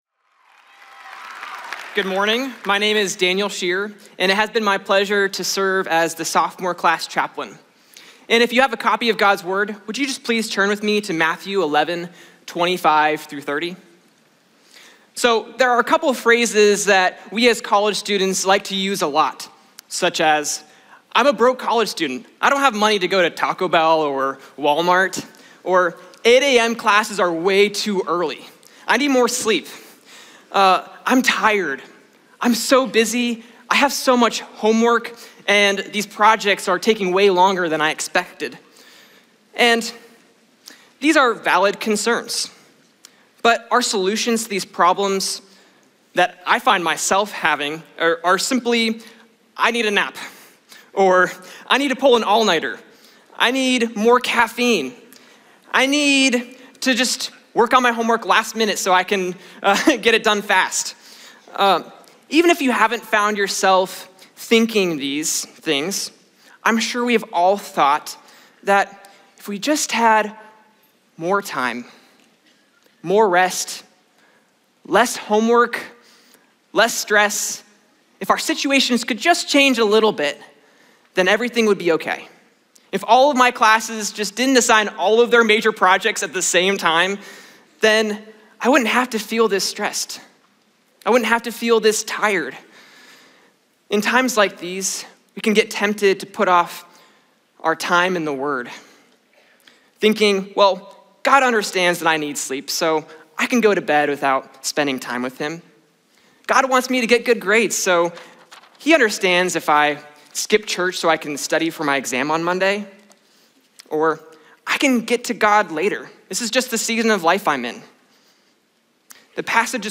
Cedarville University Chapel Message